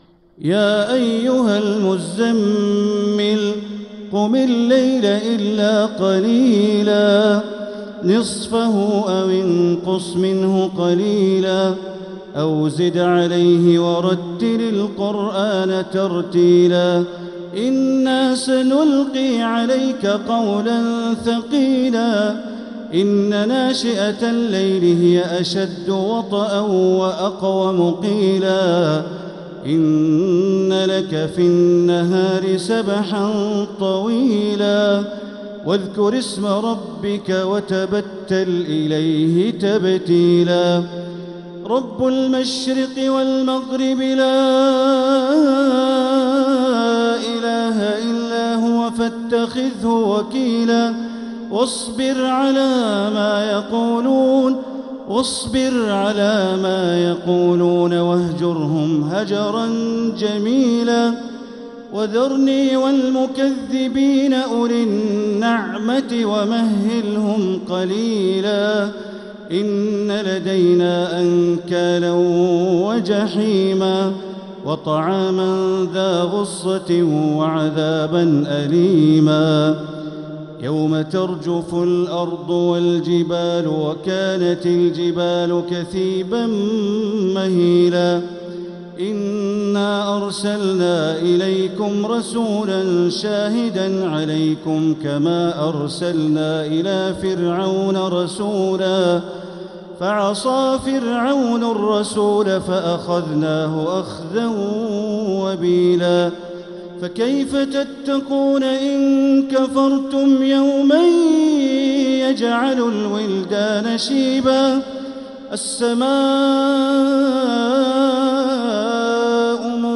سورة المزمل | مصحف تراويح الحرم المكي عام 1446هـ > مصحف تراويح الحرم المكي عام 1446هـ > المصحف - تلاوات الحرمين